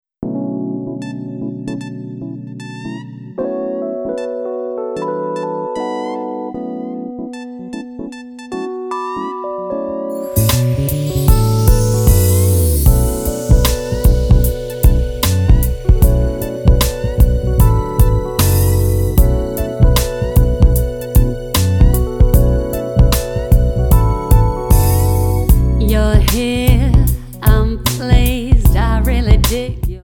--> MP3 Demo abspielen...
Tonart:Bm Multifile (kein Sofortdownload.